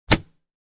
hitsound1.wav